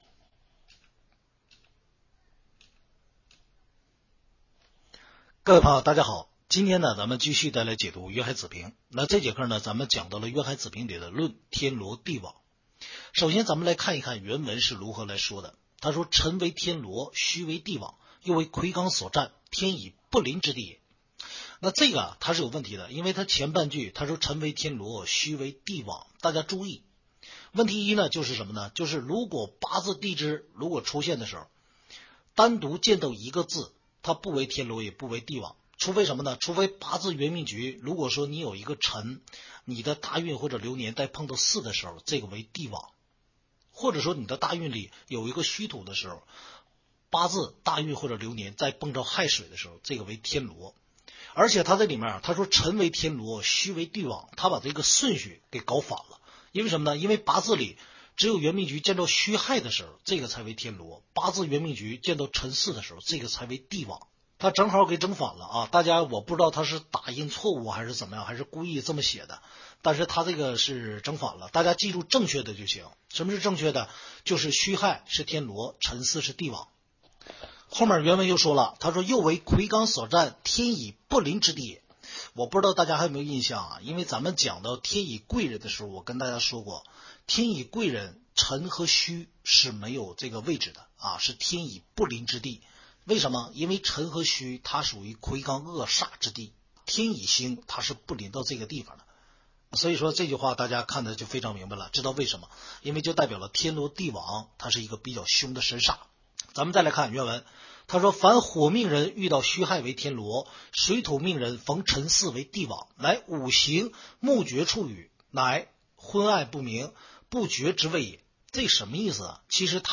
听书渊海子平白话文